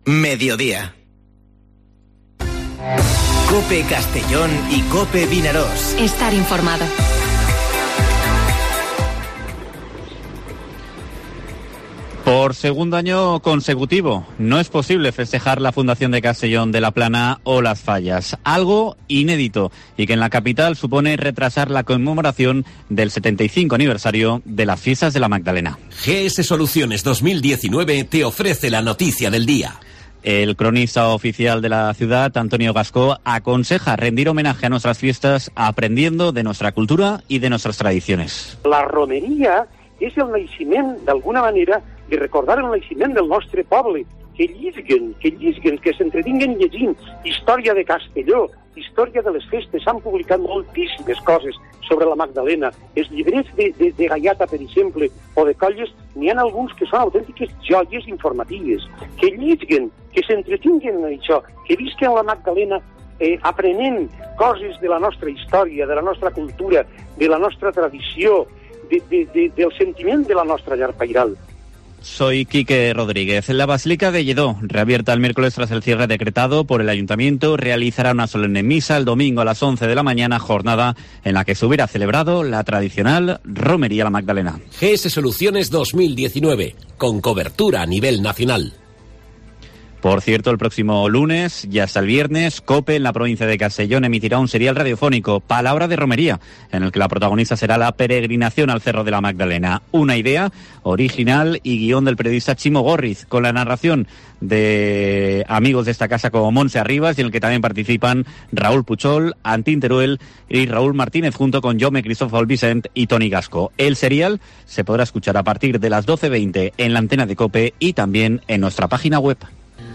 Informativo Mediodía COPE en la provincia de Castellón (05/03/2021)